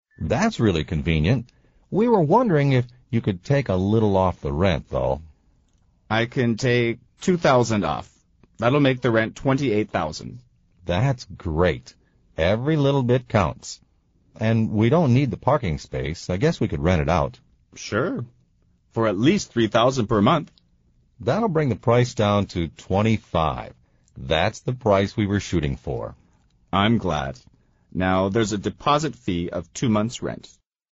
美语会话实录第153期(MP3+文本):Every little bit counts